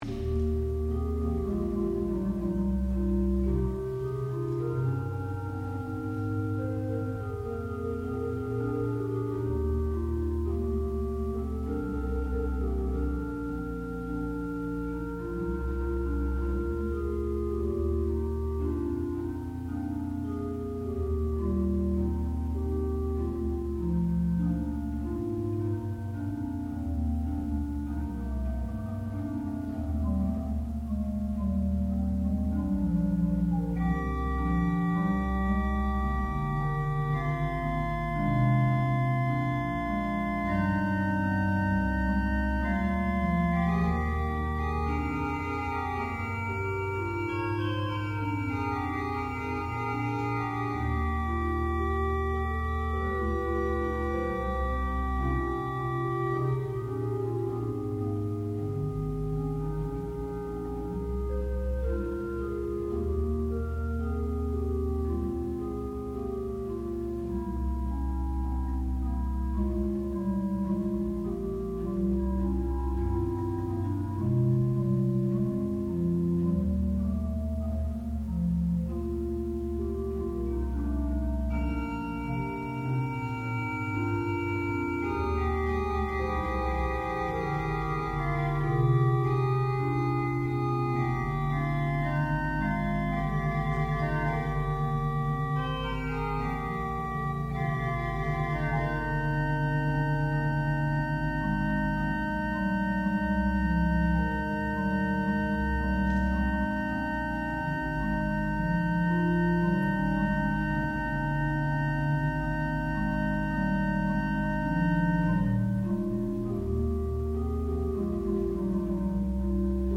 sound recording-musical
classical music
Graduate Recital
organ